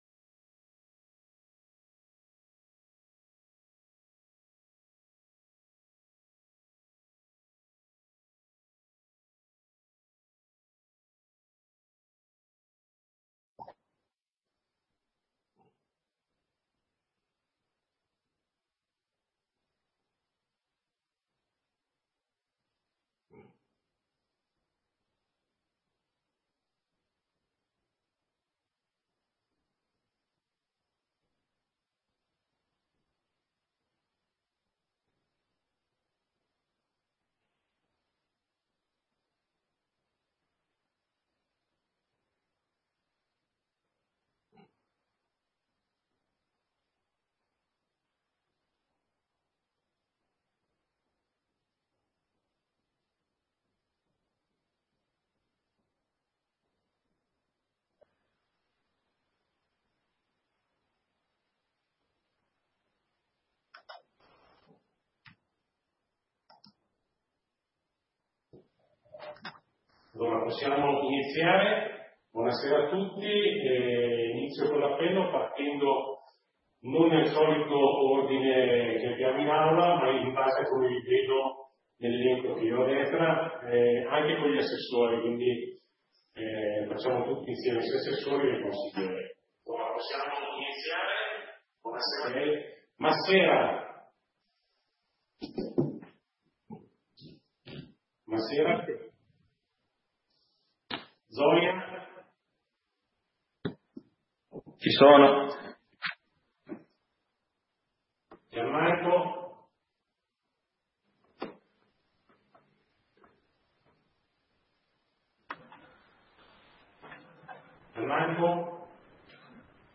Seduta consiglio comunale del 30 ottobre 2020 - Comune di Sondrio
Ordine del giorno ed audio della seduta consiliare del Comune di Sondrio effettuata nella data sotto indicata.